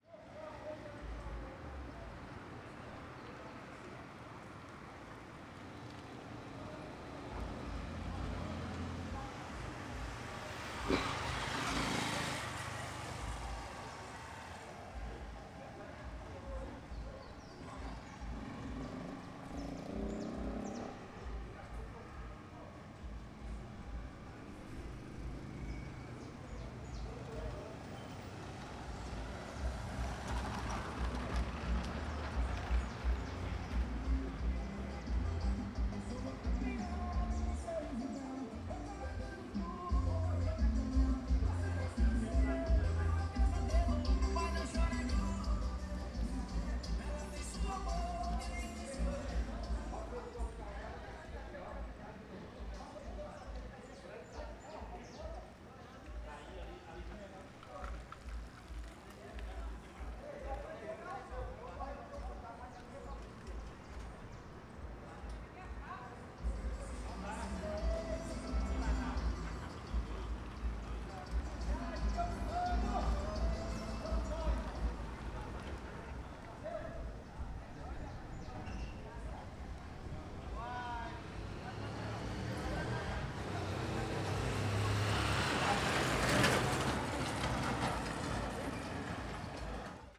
CSC-04-217-OL- Ambiente em oficina mecanica de posto colocando musica alta.wav